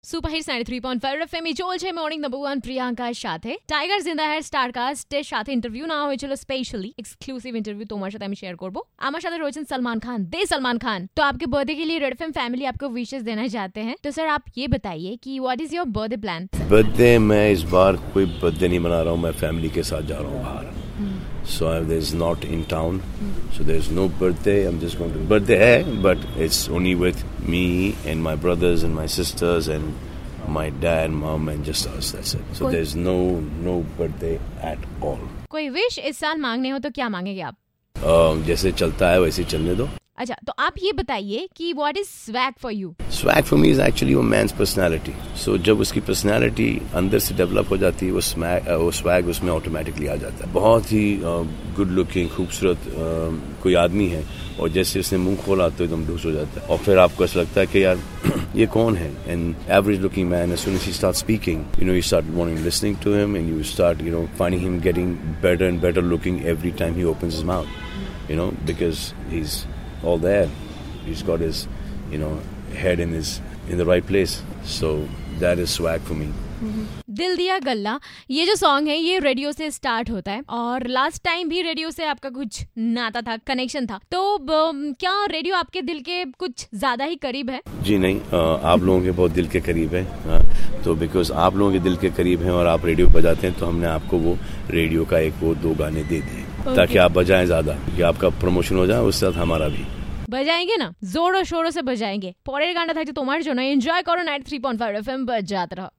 EXCLUSIVE CONVERSATION WITH SALMAN KHAN